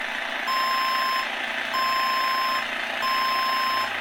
Box Truck In Reverse Drive Sound Button - Free Download & Play